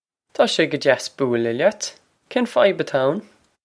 Pronunciation for how to say
Taw shey guh jass boo-lah lyat. Kayn fy-ib a-taw ow-n?
This is an approximate phonetic pronunciation of the phrase.